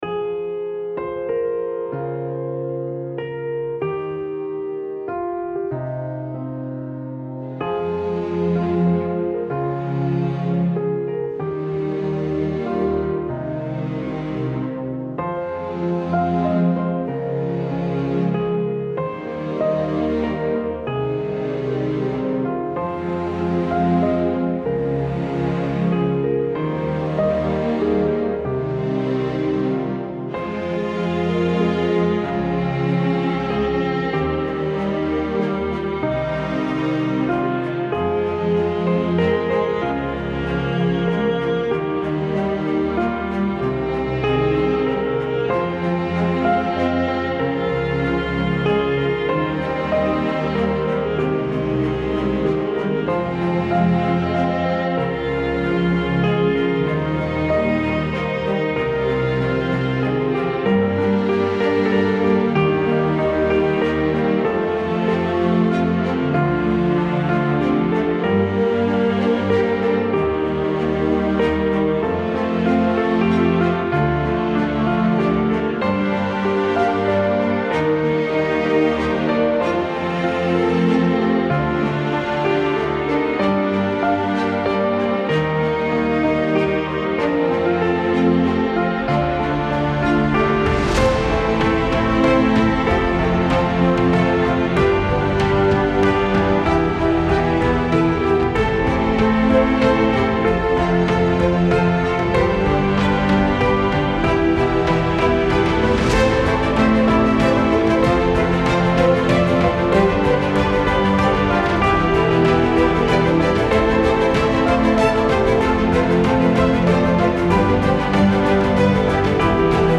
in Hymns by